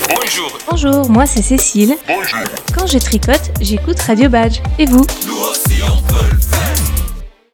jingle fait maison